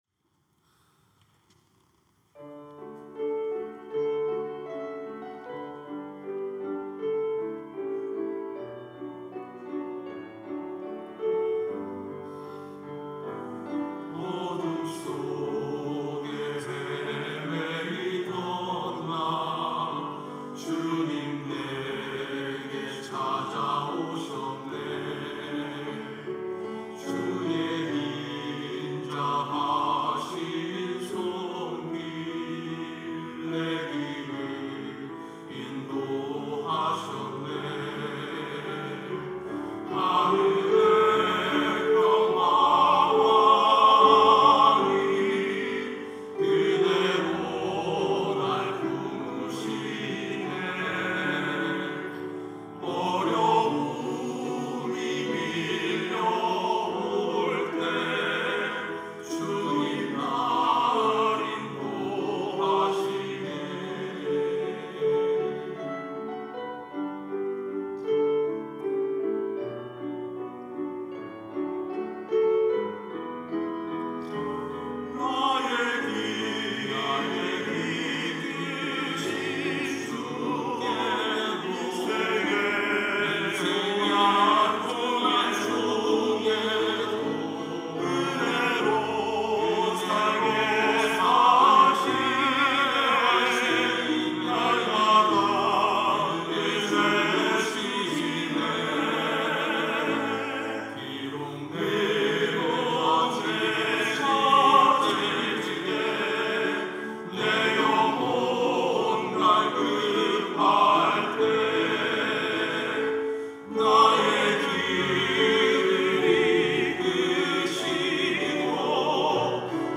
천안중앙교회
찬양대 휘오스